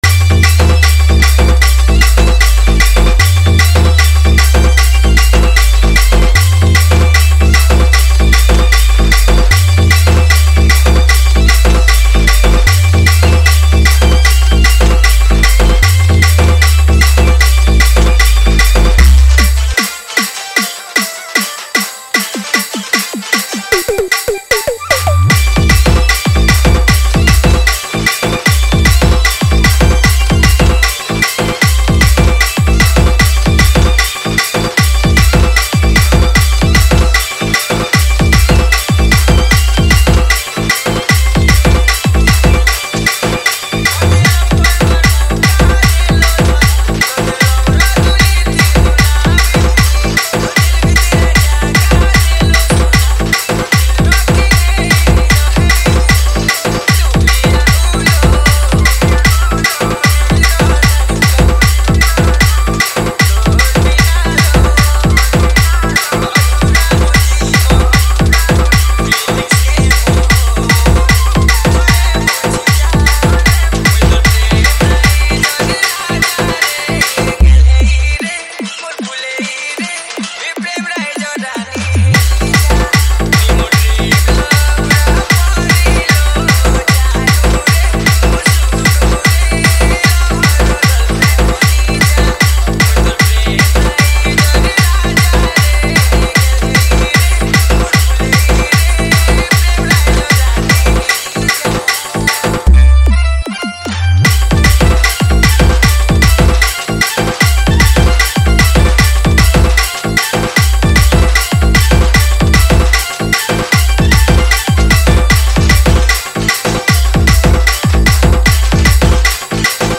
Category:  Sambalpuri Dj Song 2024